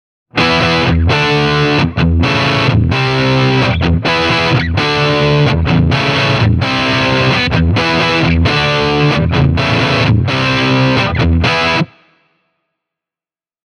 LES PAUL SÄRÖLLÄ
Referenssikaiutin (Bluetone Shadows Jr. -kombo 10-tuumaisella WGS Green Beret -kaiuttimilla; Shure SM57):
wgs-green-beret-e28093-les-paul-overdrive.mp3